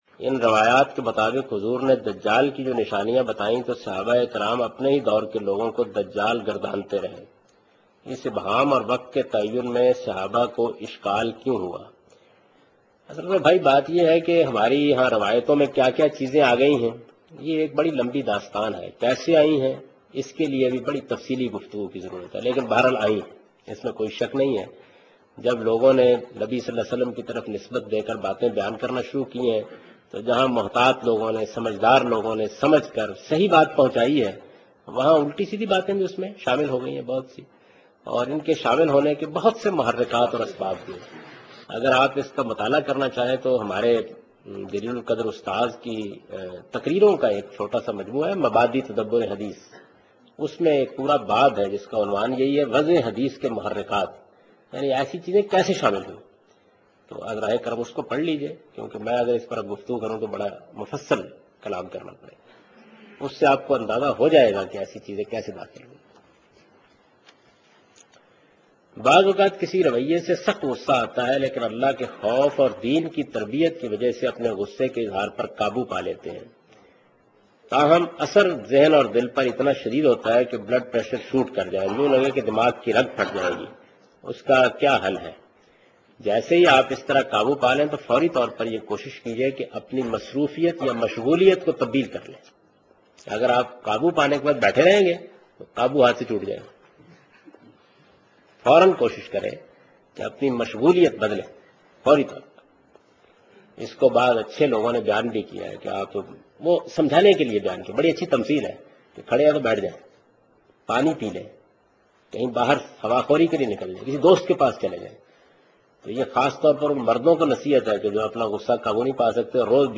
اس پروگرام میں جاوید احمد غامدی دینی مسایل کے جوابات-۴ کے متعلق بیان کر رہے ہیں